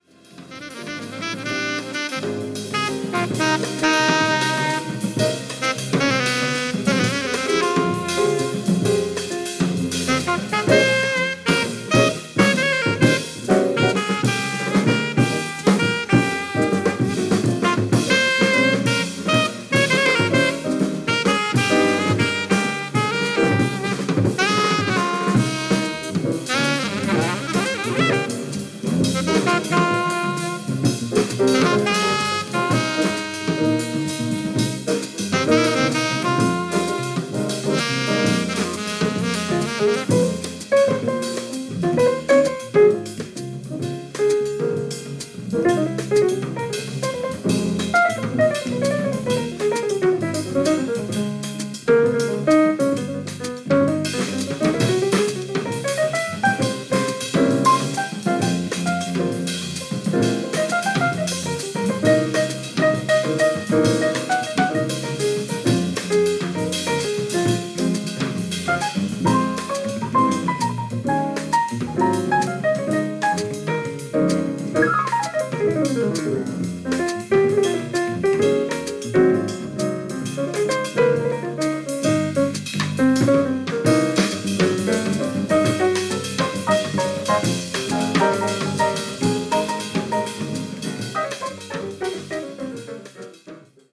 Van Gelder Recording Studioに集まった4人、ステキです！！！